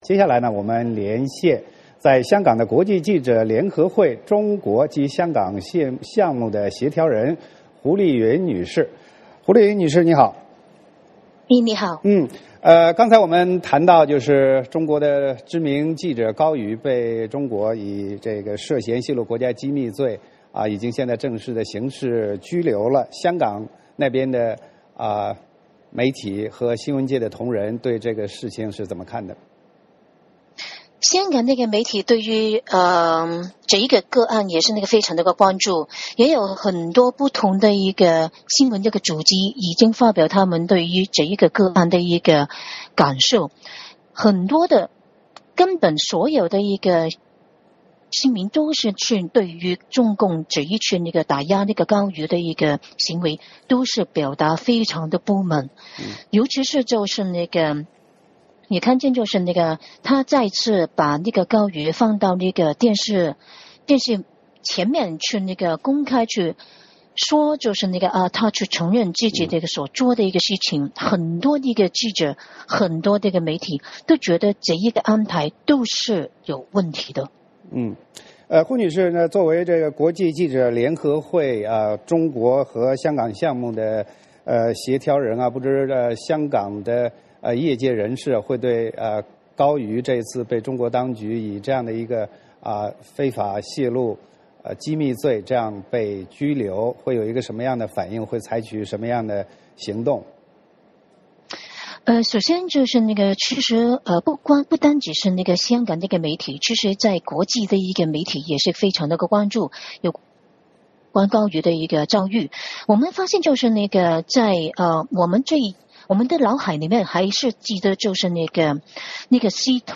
VOA连线：高瑜遭当局刑事拘留 香港媒体反映